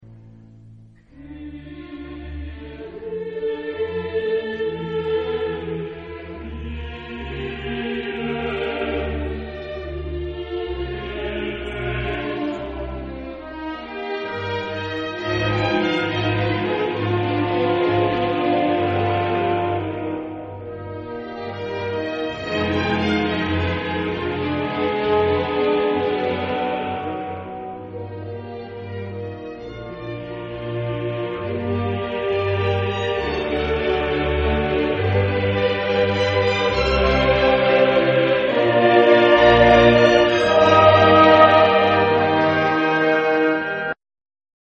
Genre-Stil-Form: geistlich ; romantisch ; Messe
Charakter des Stückes: adagio
Chorgattung: SATB  (4 gemischter Chor Stimmen )
Solisten: SATB  (4 Solist(en))
Tonart(en): d-moll